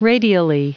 Prononciation du mot radially en anglais (fichier audio)
Prononciation du mot : radially